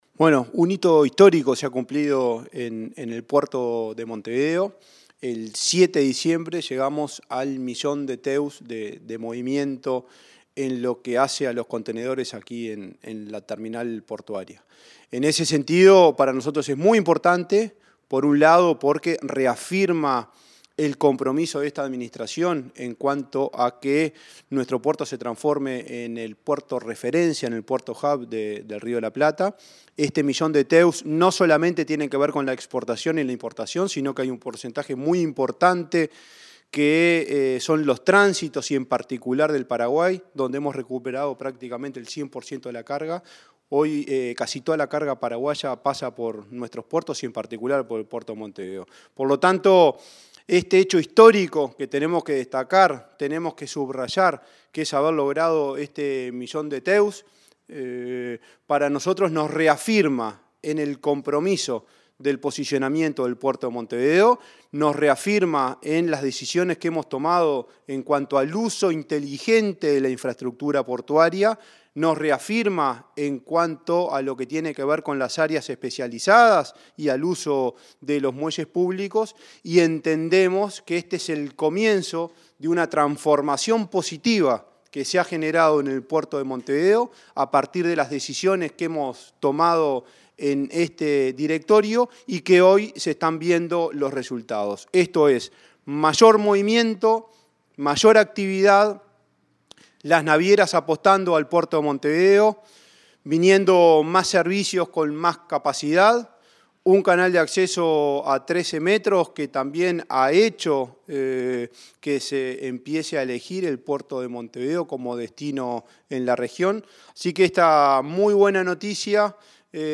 Entrevista al presidente de la ANP, Juan Curbelo